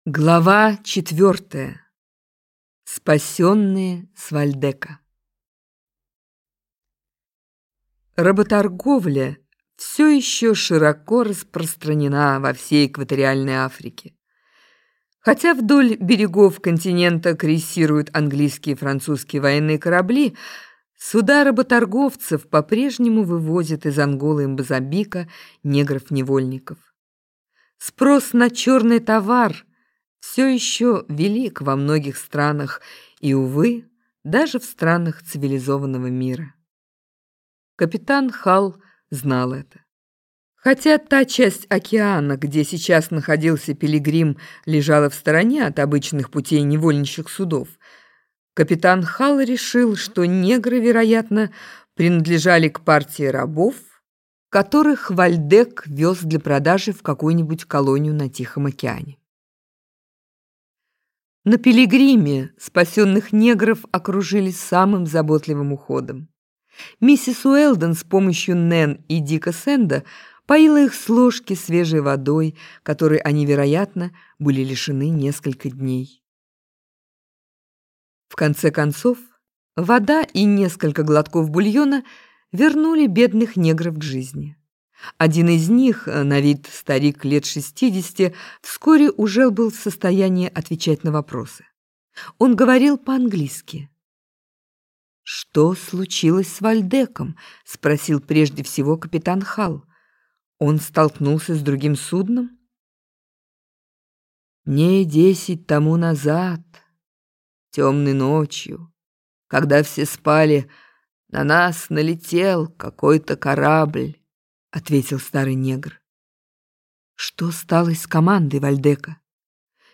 Аудиокнига Пятнадцатилетний капитан - купить, скачать и слушать онлайн | КнигоПоиск